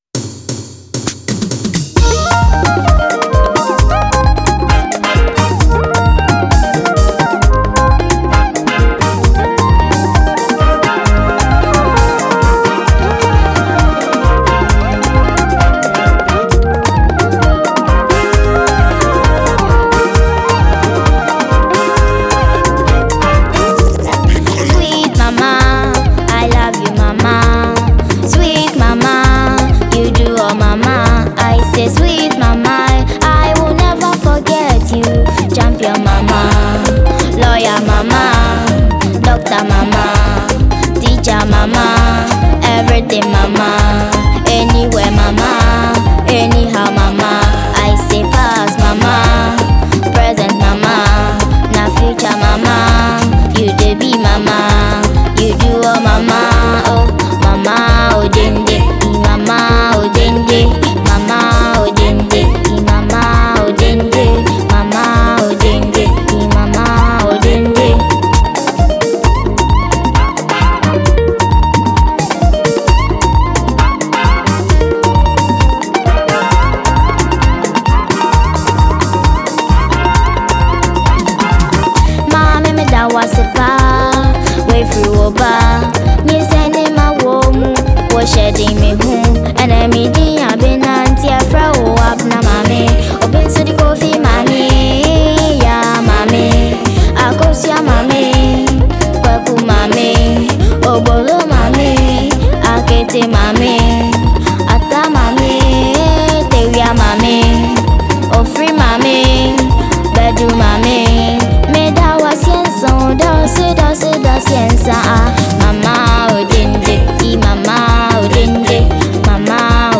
Ghanaian mp3 song